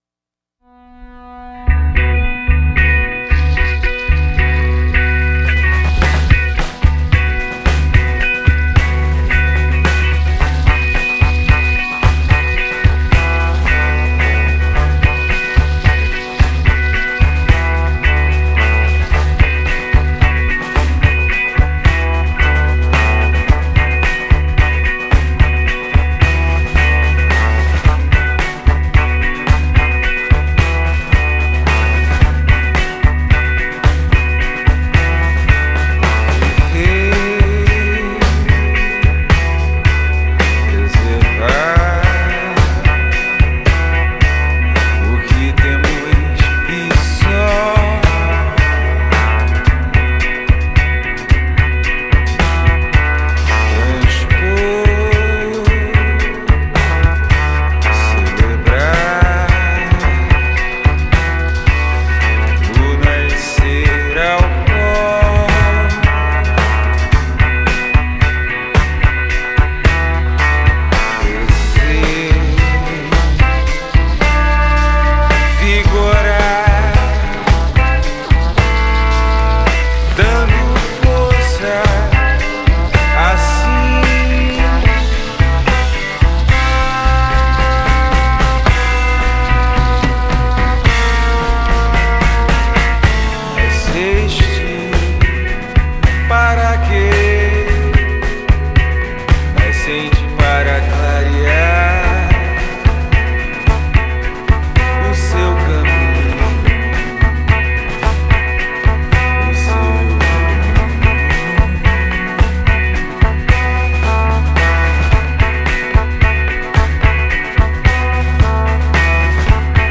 EstiloAlternativo